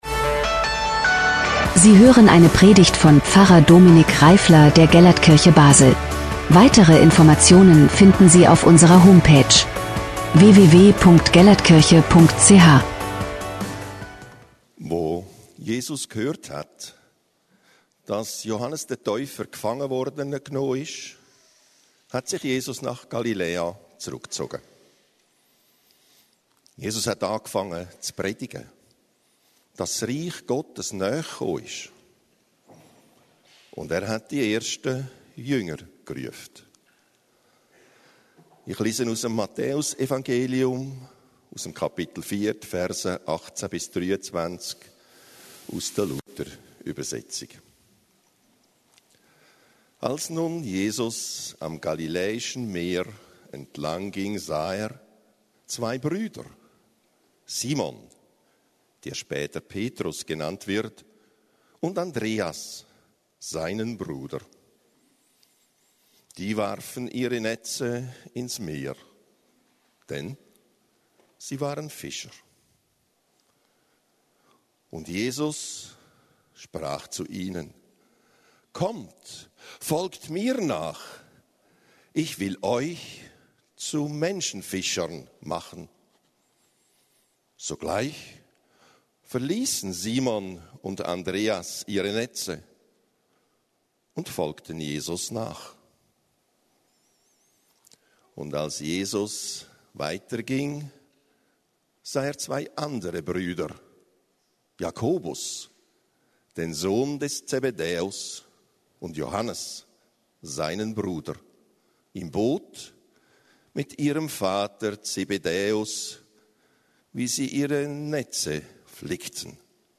Letzte Predigt